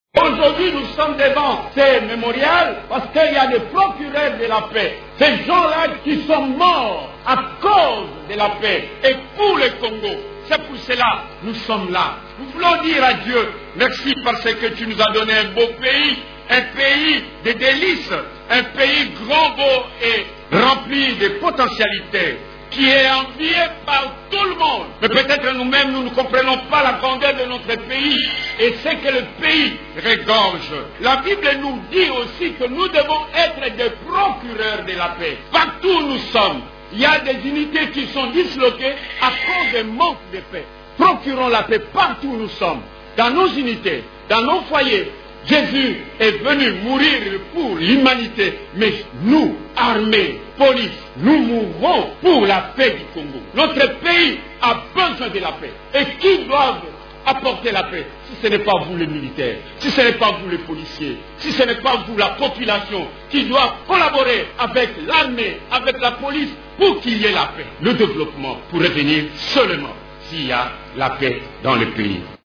L'exhortation des aumôniers a été faite lors d’une brève cérémonie officielle qui a regroupé les hauts officiers et hommes de troupes en ce jour dédié aux soldats morts pour la défense de la patrie et ceux encore sous le drapeau.
Voici un extrait de cette exhortation: